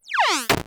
close_shut.wav